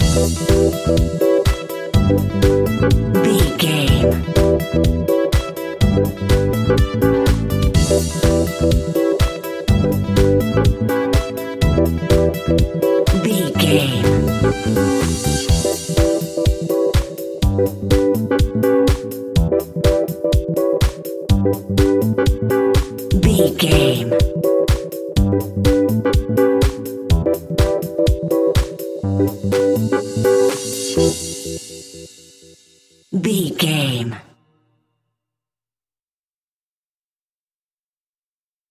Aeolian/Minor
groovy
energetic
funky
bass guitar
drums
electric piano
synthesiser
funky house
disco
upbeat